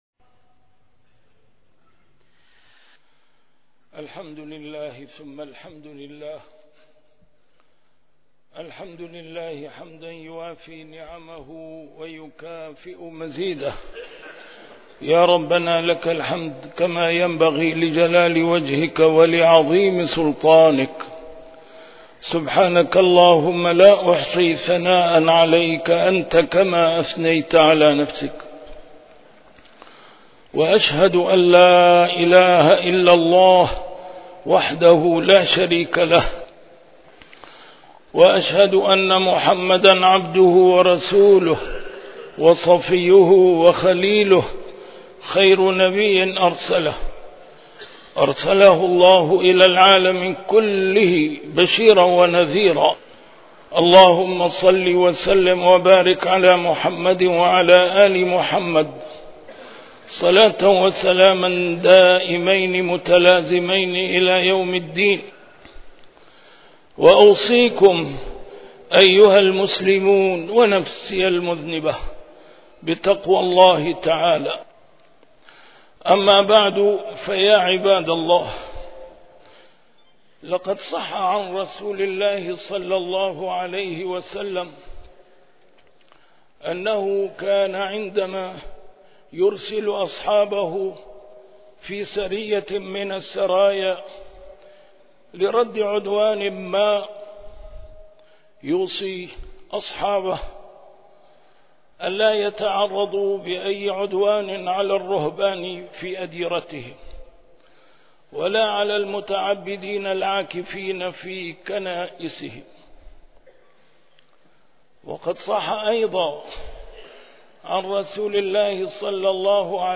A MARTYR SCHOLAR: IMAM MUHAMMAD SAEED RAMADAN AL-BOUTI - الخطب - يَا أَيُّهَا الَّذِينَ آمَنُواْ ادْخُلُواْ فِي السِّلْمِ كَآفَّةً